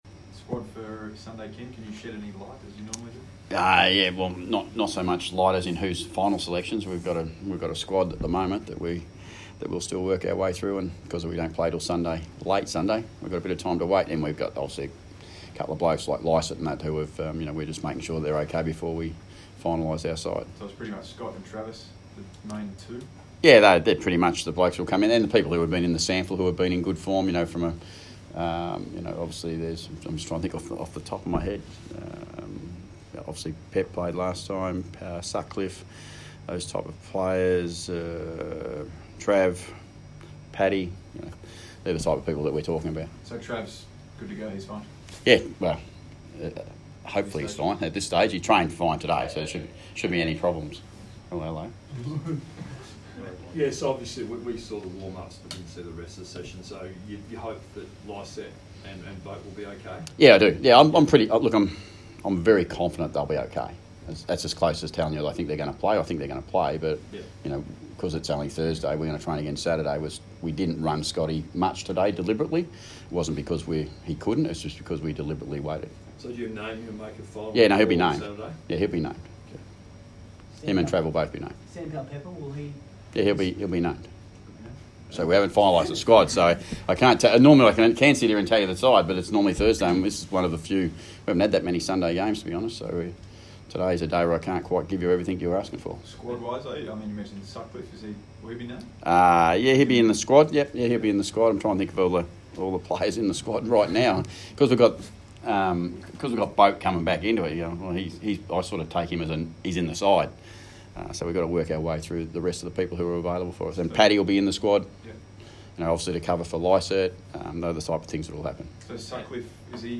Ken Hinkley press conference - Thursday 11 July, 2019